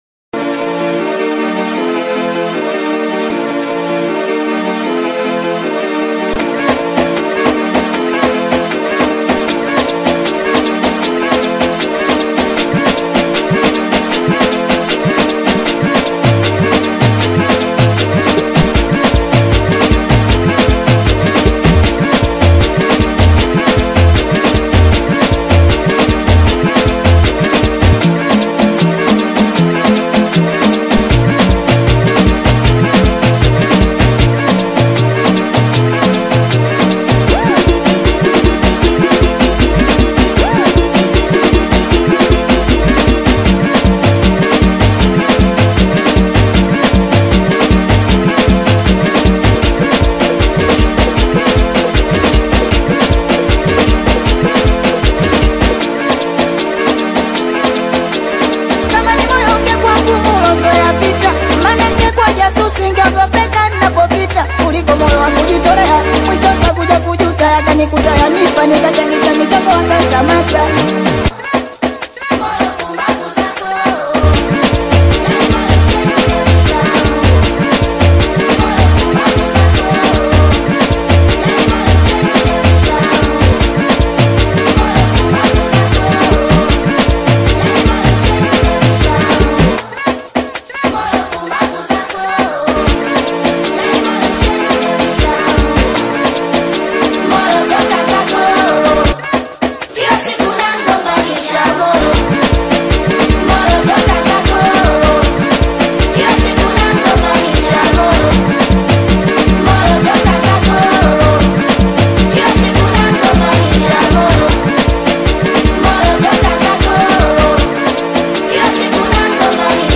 AudioSingeli